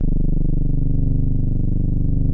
MOOG #9  G1.wav